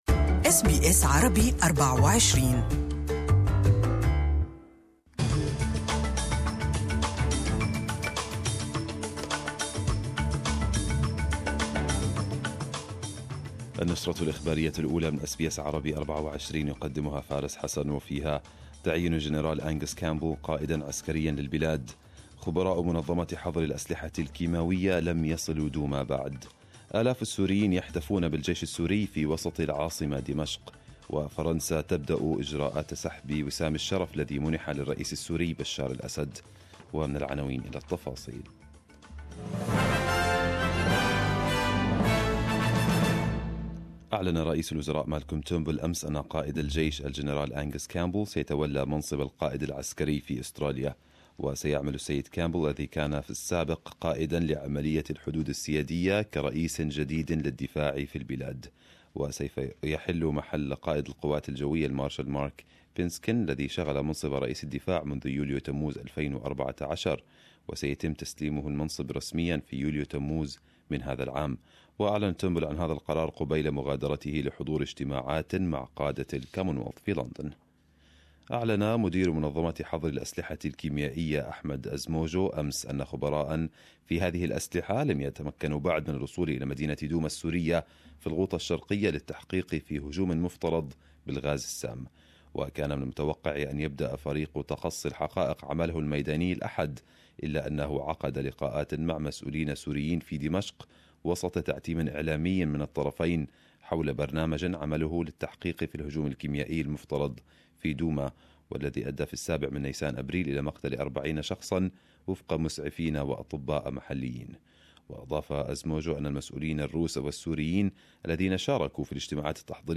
Arabic News Bulletin 17/04/2018